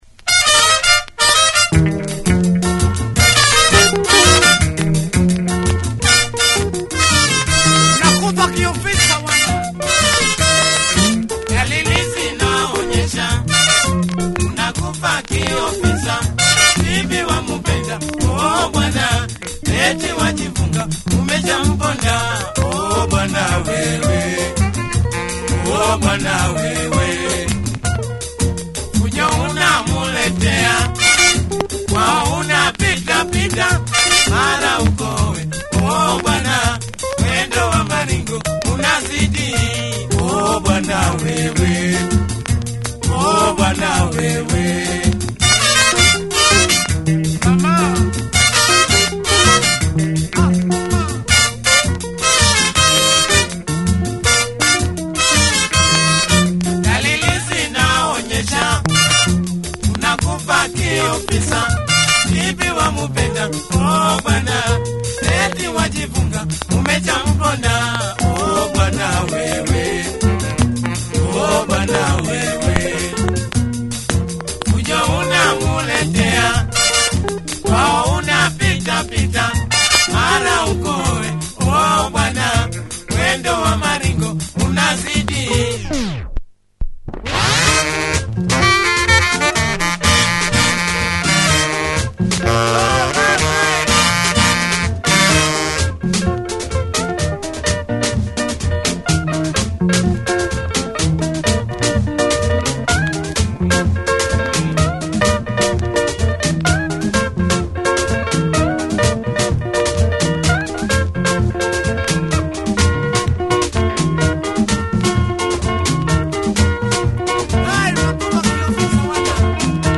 Prominent horns in this slice of quality Tanzania rumba!